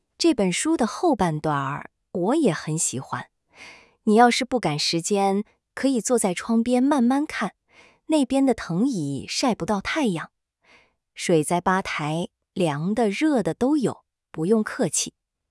智东西选择了知性女声，上传的指令是“声音轻柔舒缓，语速很慢，带着安抚人心的温度，说话时像在给客人递一杯热咖啡，语气温柔又有耐心，像开了几十年书店的老板娘。”
生成的音频中，老板娘说话整体语速偏慢、换气舒缓，没有急促感，字句之间留白自然，整体符合语言生成的需求。